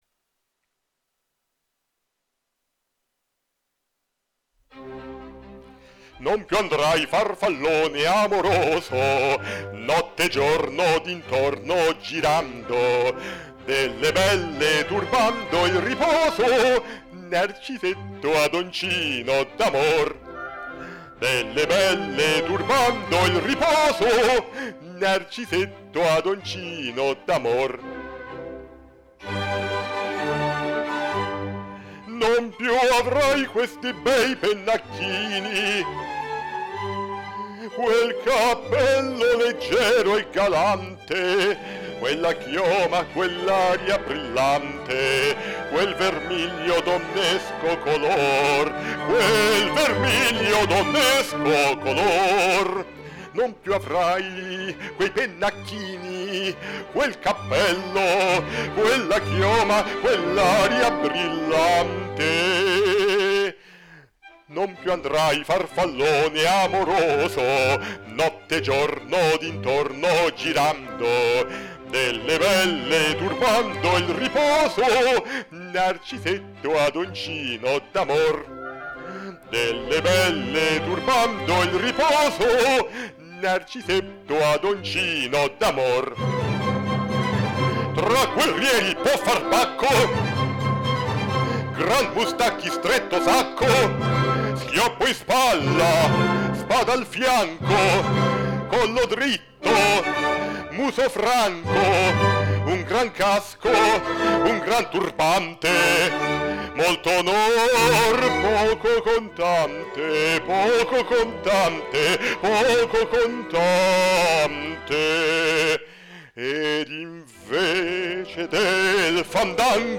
E va bene, vi accontento... eccovi, di seguito, il link per ascoltare una registrazione della mia voce, ottenuta cantando sopra un sottofondo preso su YouTube:
e io non mi sto più tanto esercitando a cantare... infatti in quella registrazione mi trovate piuttosto giù di voce.